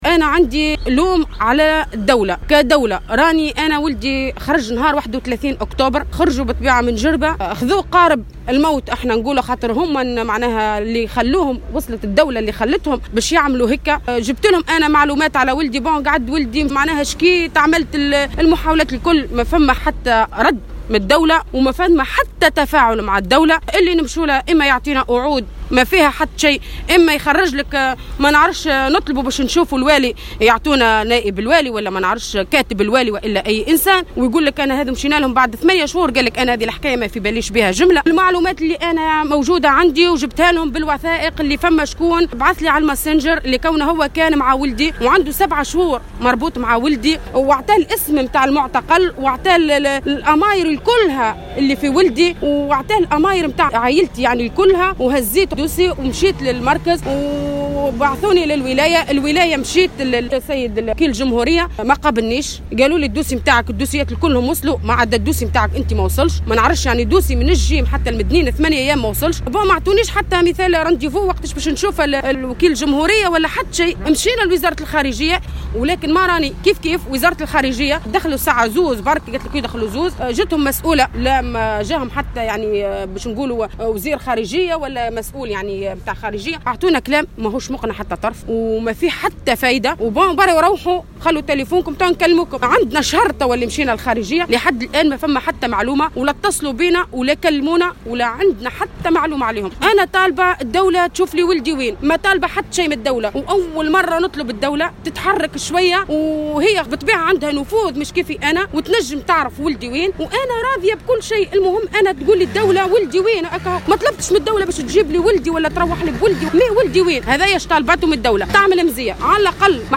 والدة أحد المفقودين